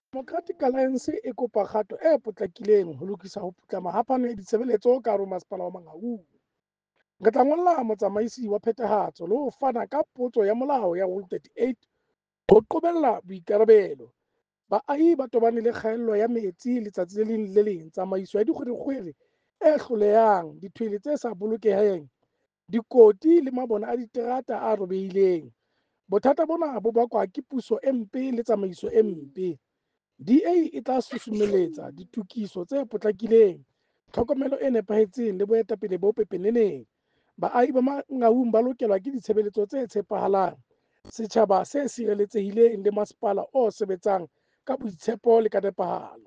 Sesotho soundbite by Cllr Kabelo Moreeng with images here, and here